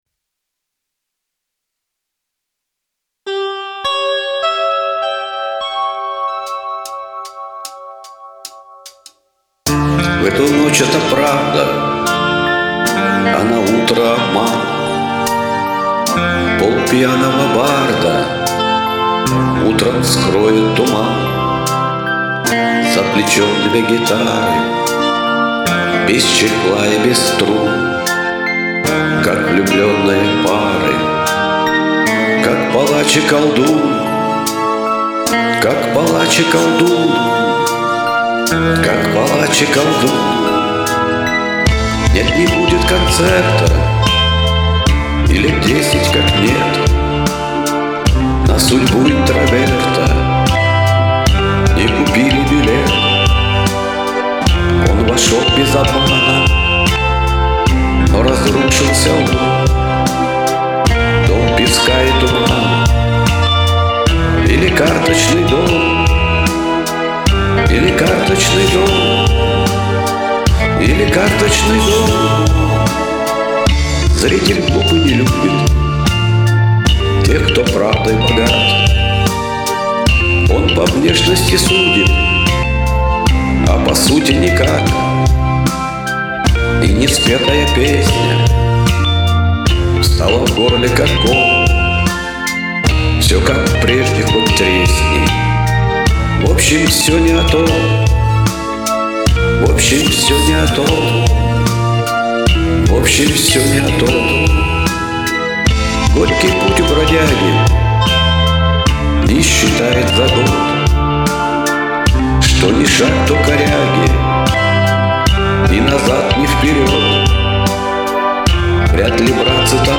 Задумывалась, как блюз, но что-то пошло не так и вильнуло не туда. Аранжировки нет, не умею.